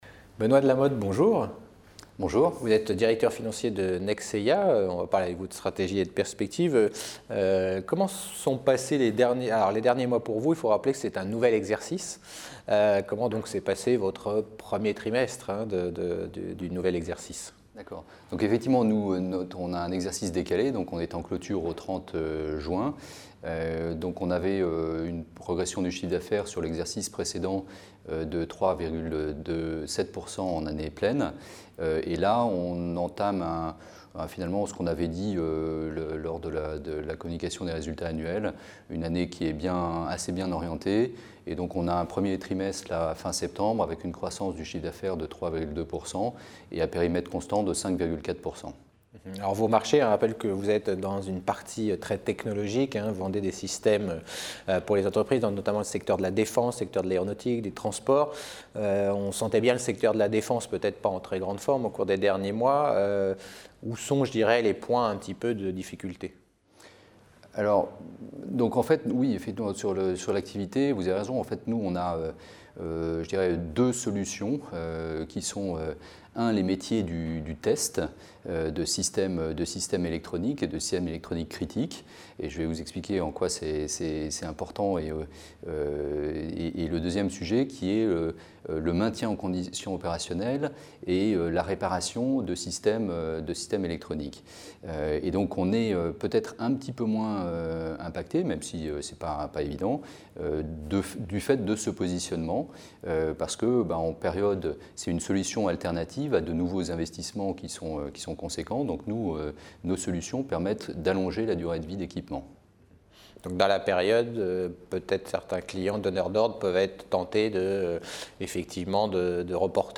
Réunion Alternext Gilbert Dupont : Stratégie et perspectives de Nexeya.